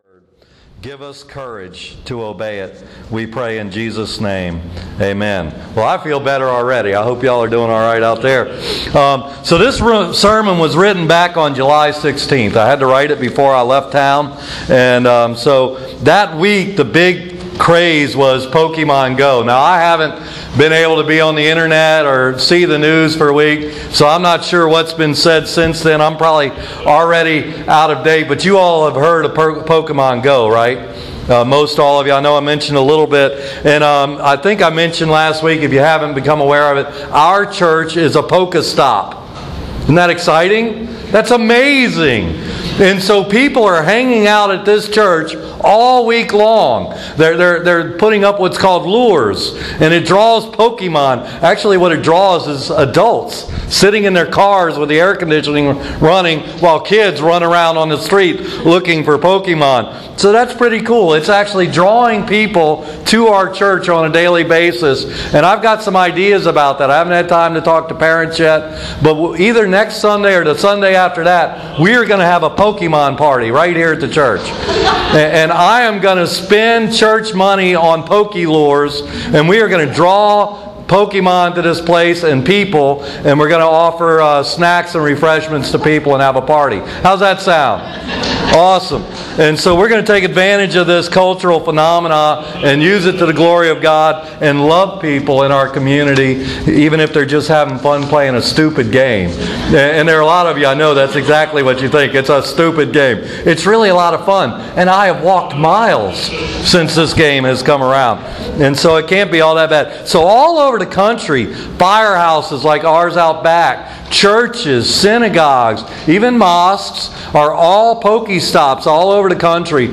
Yes I worked a little Pokemon Go into the sermon.